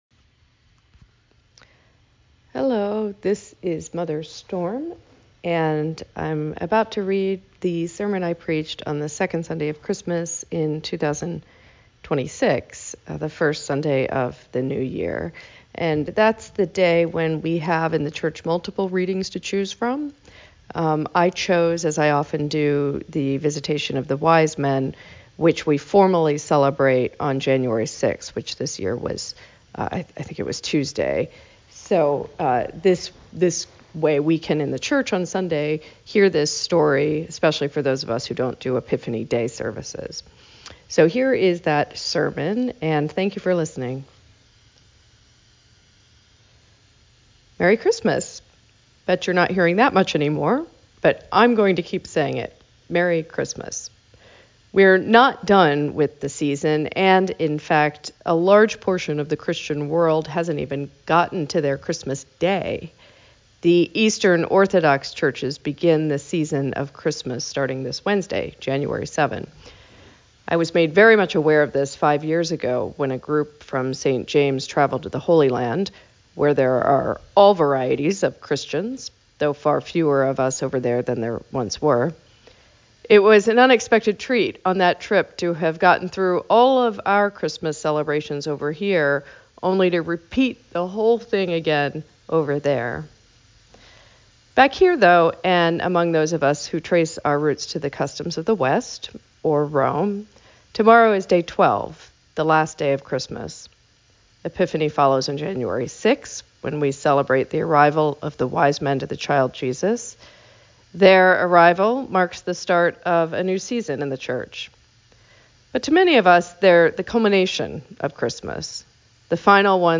Sermon Podcast | Church of St. James the Less